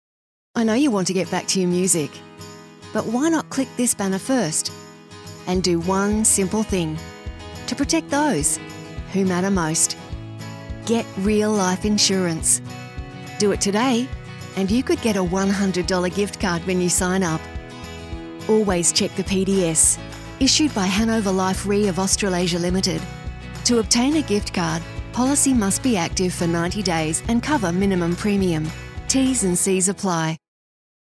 • Natural
• Rode Procaster mic